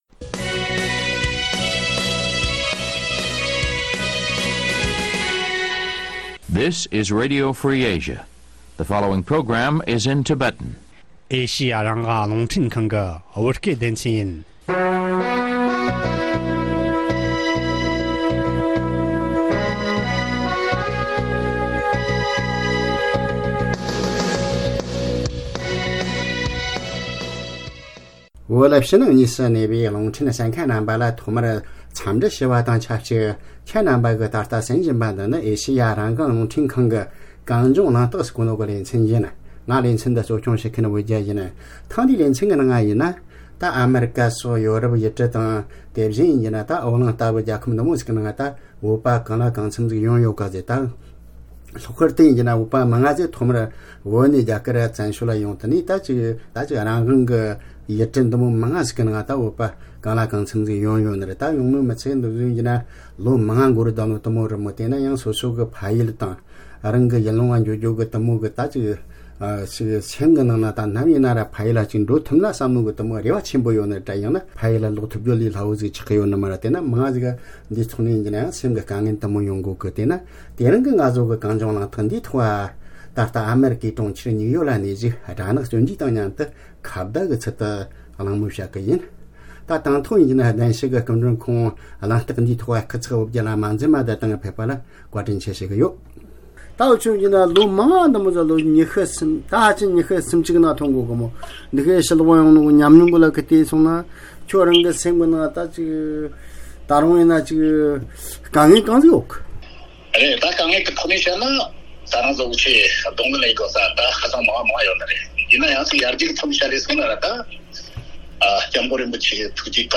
ཐེངས་འདིའི་གངས་ལྗོངས་གླེང་སྟེགས་ཀྱི་ལེ་ཚན་དུ་ནུབ་ཕྱོགས་ཡུལ་ལུང་གང་སར་གནས་པའི་བོད་མི་ཚོའི་གནབ་བབ་གང་ཅིའི་ཐད་གླེང་མོལ།